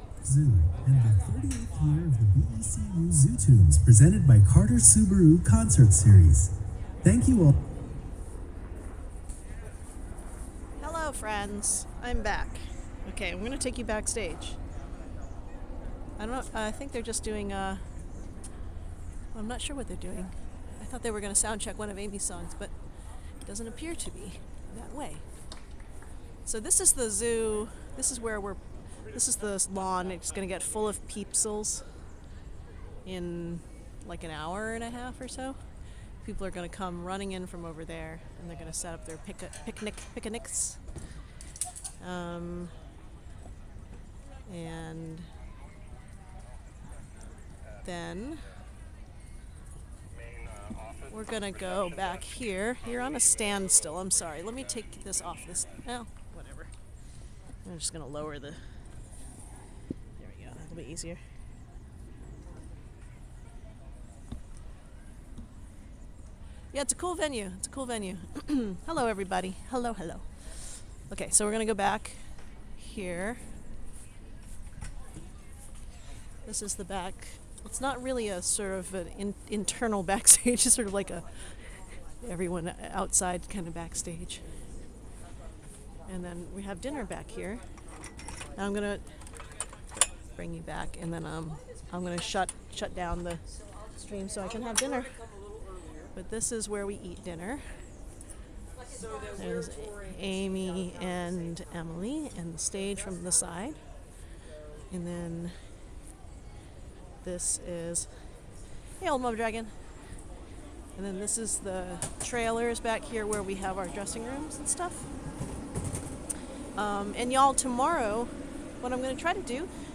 lifeblood: bootlegs: 2022-06-15: woodland park zoo - seattle, washington (soundcheck)